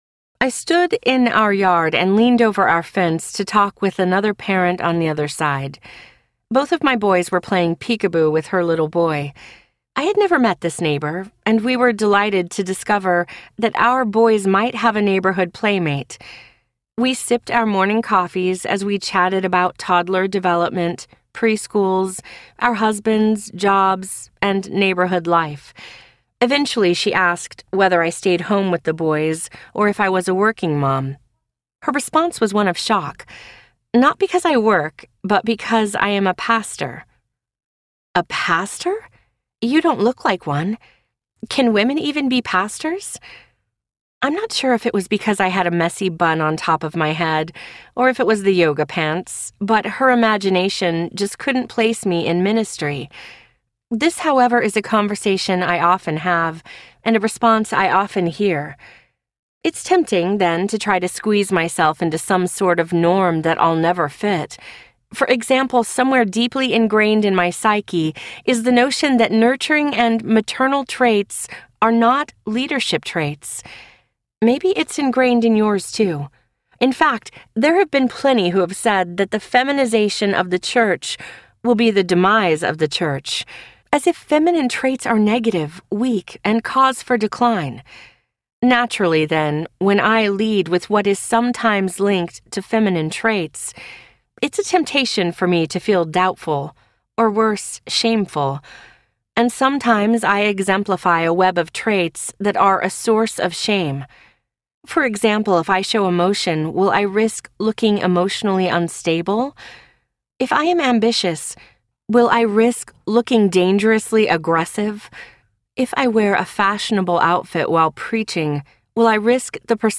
Emboldened Audiobook
5.53 Hrs. – Unabridged